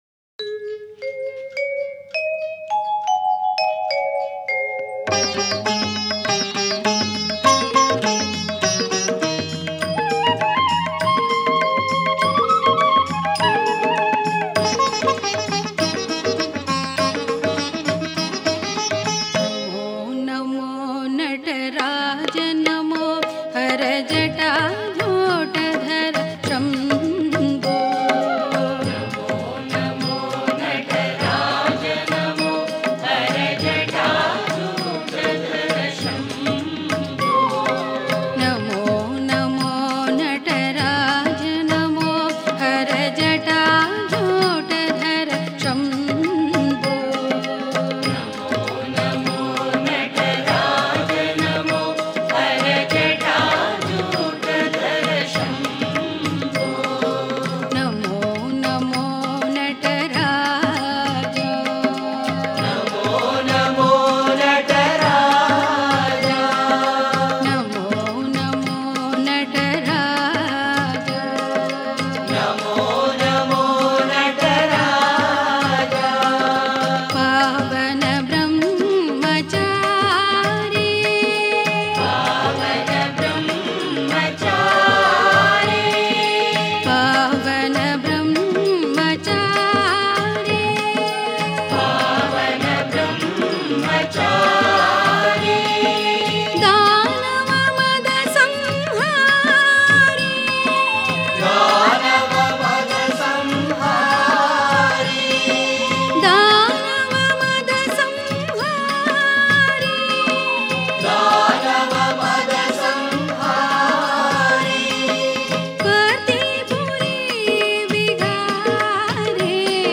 Home | Bhajan | Bhajans on various Deities | Shiva Bhajans | 37 NAMO NAMO NATARAJA NAMO